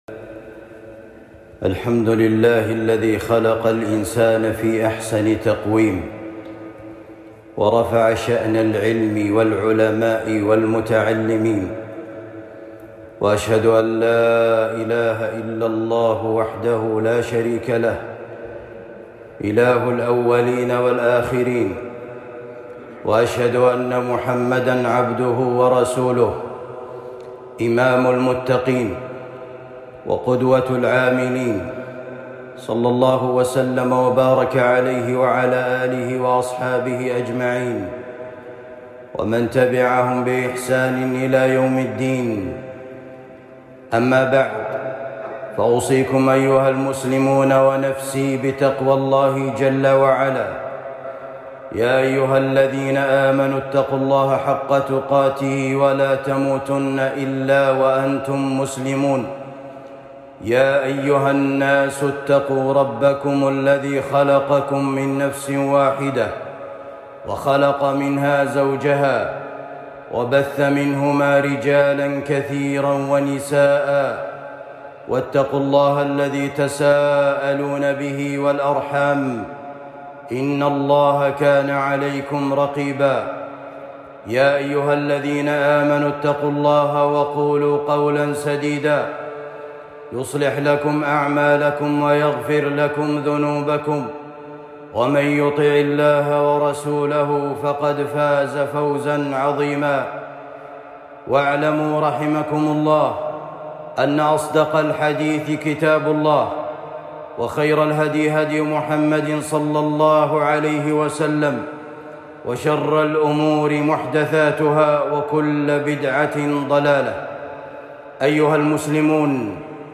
فضل العلم واهميته التحصيل العلمي خطبة جمعة